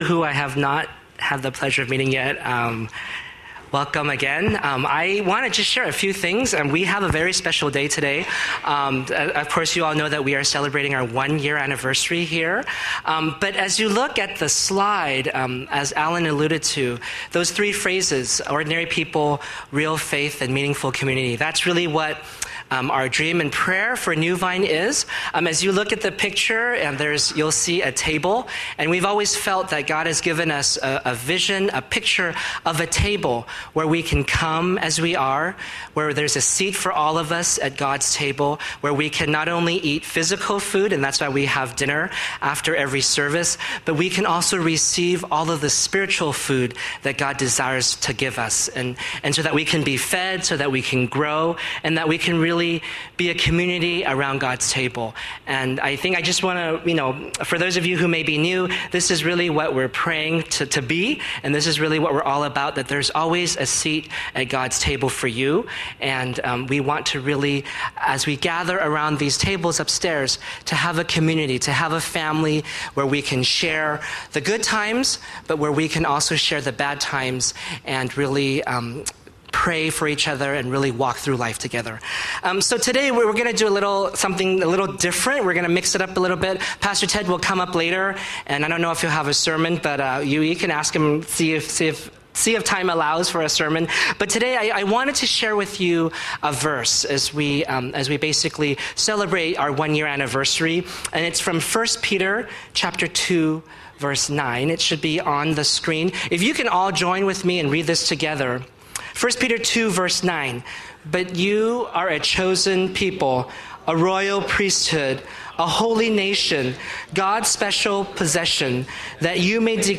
Panel Sharing: One Year, One Family, One Faithful God
Speaker: Testimonies & Sharing